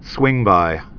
(swĭngbī)